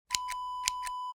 Scissors Snip Wav Sound Effect #5
Description: The sound of a pair of scissors snipping
Properties: 48.000 kHz 16-bit Stereo
A beep sound is embedded in the audio preview file but it is not present in the high resolution downloadable wav file.
Keywords: scissors, snip, snipping, cut, cutting, hair, click, clicking
scissors-snip-preview-5.mp3